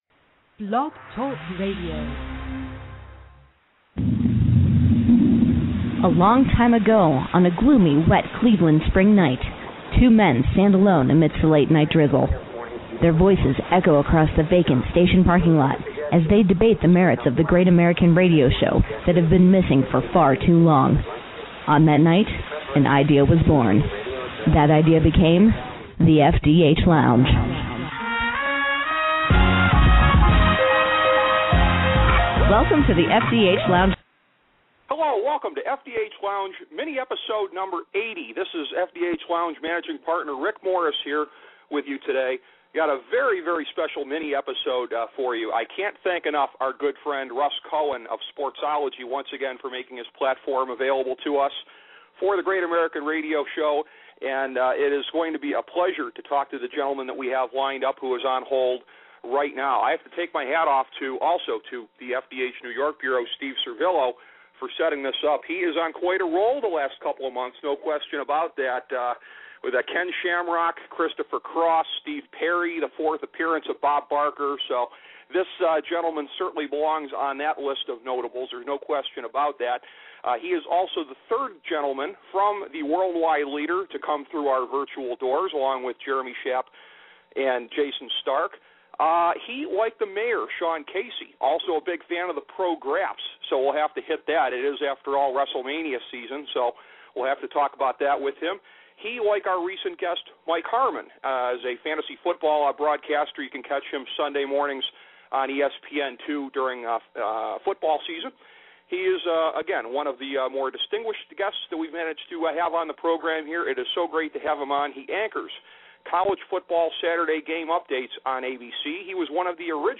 A conversation with ESPN's Robert Flores
The FDH Lounge program, once again making a guest appearance on the Sportsology channel, presents a discussion with Robert Flores of ESPN.